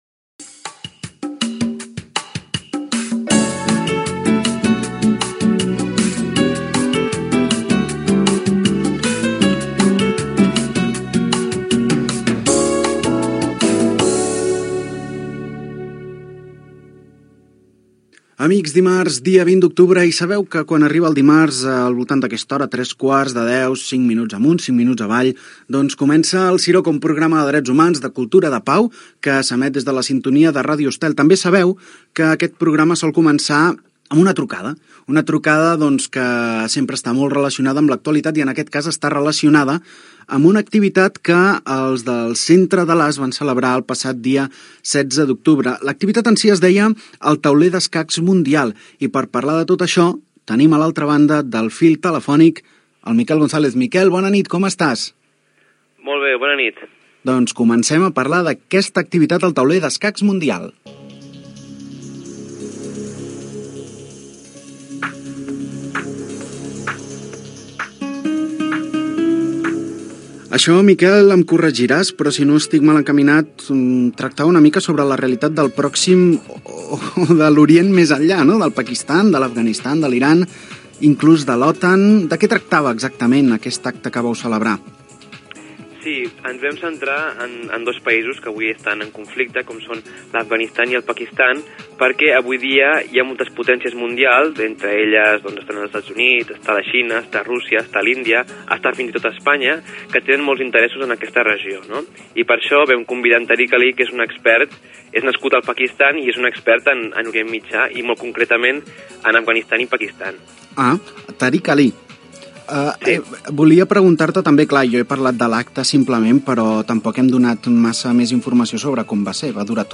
Sintonia del programa, data, presentació
Divulgació